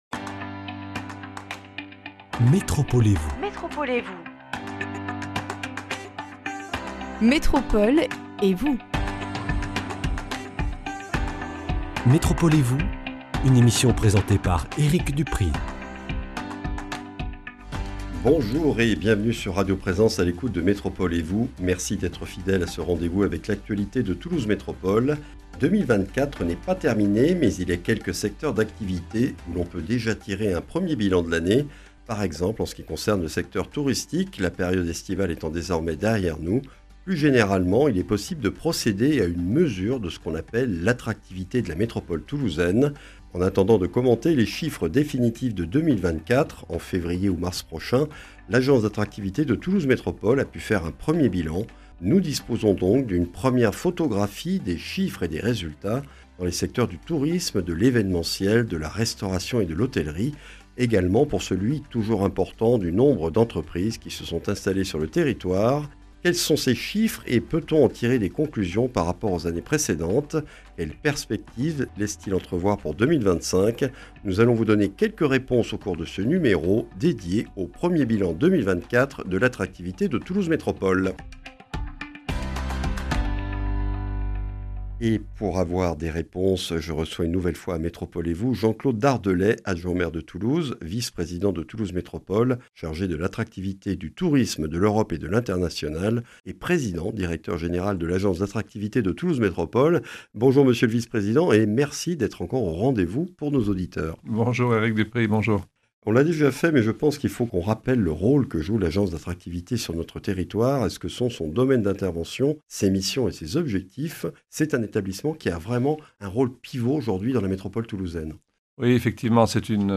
Un 1er bilan 2024 de l’attractivité de Toulouse Métropole a pu être réalisé à fin septembre. Jean-Claude Dardelet, vice-président de Toulouse Métropole chargé de l’attractivité, du tourisme, de l’Europe et de l’international, PDG de l’Agence d’attractivité, analyse avec nous ces 1ers chiffres et les tendances observées pour l’événementiel, le tourisme, l’hébergement et l’implantation d’entreprises.